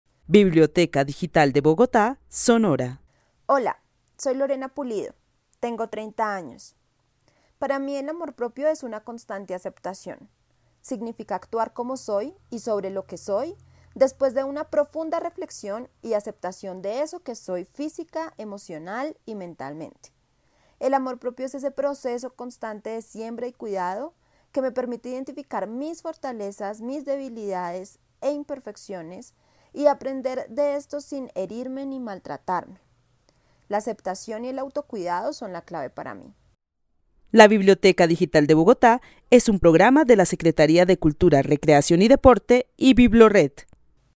Narración oral de una mujer de 30 años que vive en la ciudad de Bogotá y quien describe el amor propio como un proceso constante de aceptación física, emocional y mental. Para ella, el amor propio le permite identificar sus debilidades, fortalezas y debilidades para aprender de ellas.